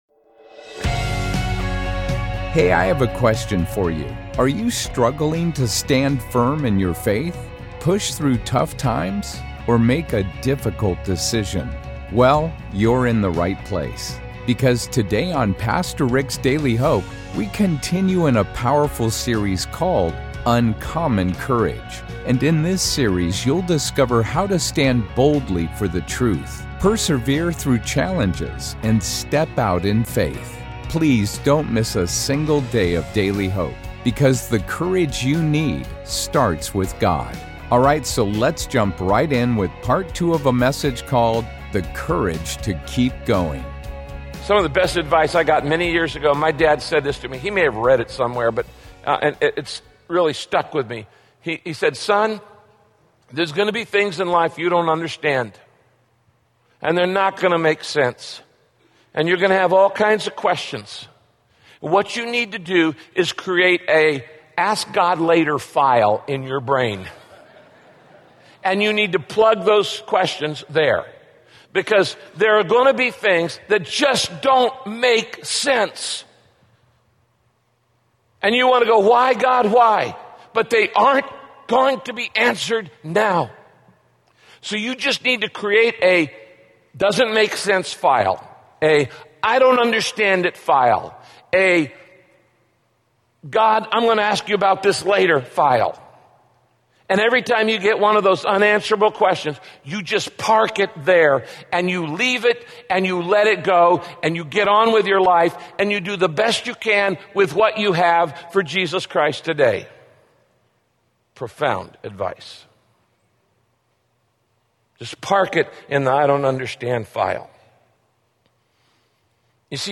When people experience major disappointment, their natural reaction is to isolate themselves. Listen to this message by Pastor Rick and discover why you most ne…